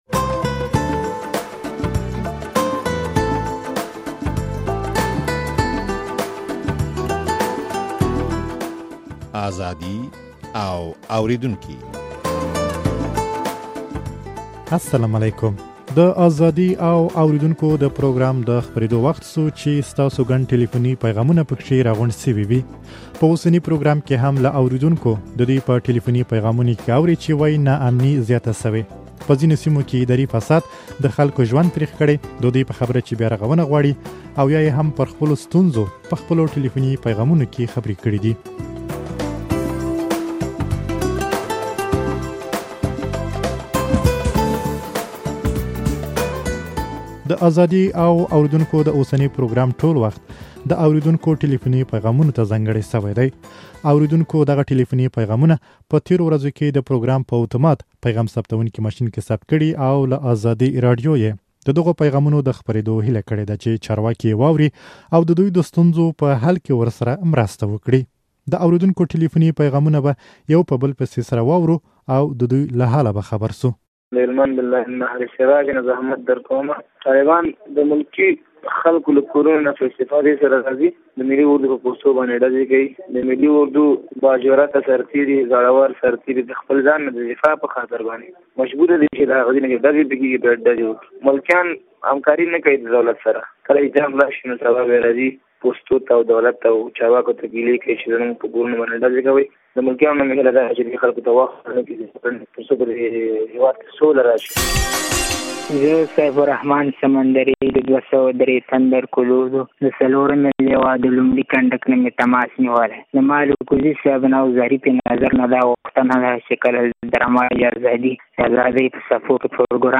په اوسني پروګرام کې هم له اورېدونکو د دوى په ټليفوني پيغامونو کې اورئ چې وايي ناامني زياته شوې، په ځينو سيمو کې اداري فساد د خلکو ژوند تريخ کړى،